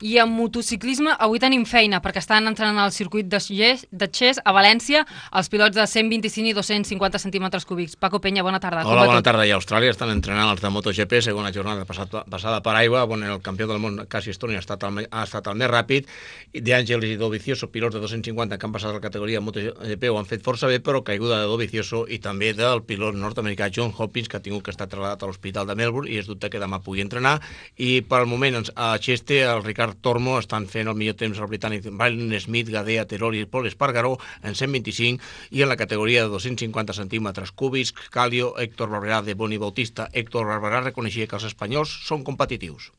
80313f312334c3974a560d5ee0fe740f010565d2.mp3 Títol Ràdio 4 Emissora Ràdio 4 Cadena RNE Titularitat Pública estatal Descripció Informació dels entrenaments de motociclisme. Gènere radiofònic Esportiu